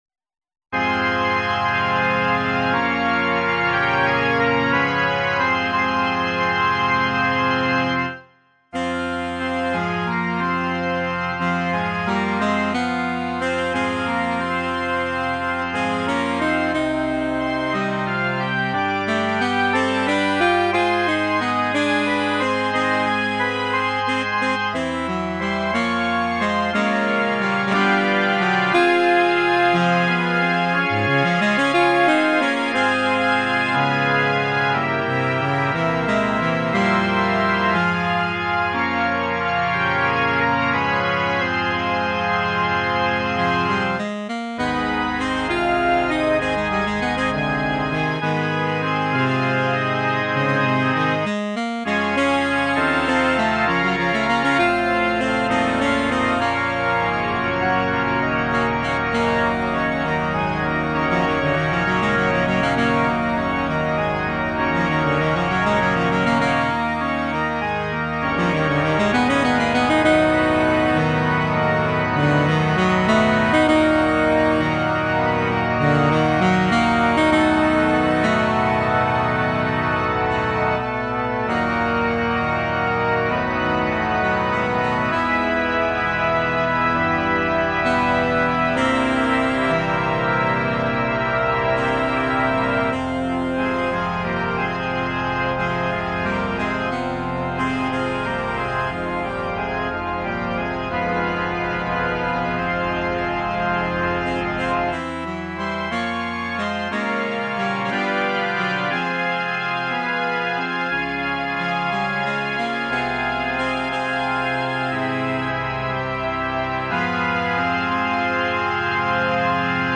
Besetzung: Instrumentalnoten für Saxophon